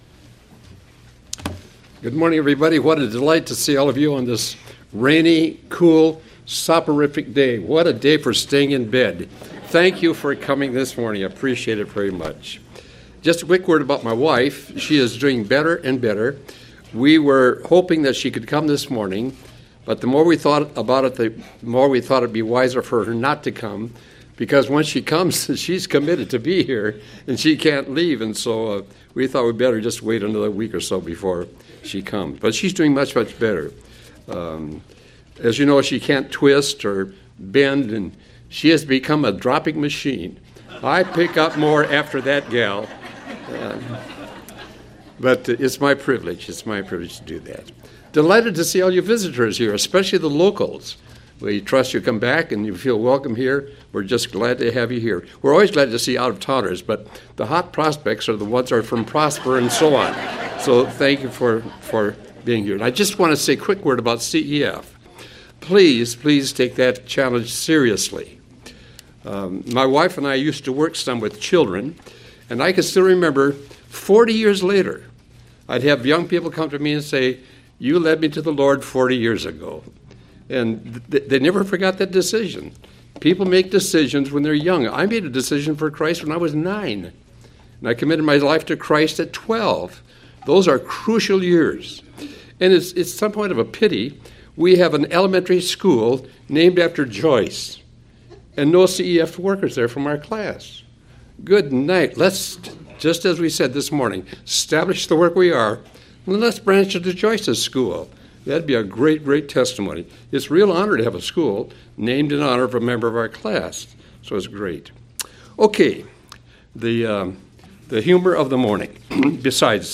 Marathon Fellowship Class Matthew Lesson 24: A Miracle Working King (Part 5) Aug 17 2014 | 00:49:43 1x 00:00 / 00:49:43 Subscribe Share RSS Feed Share Link Embed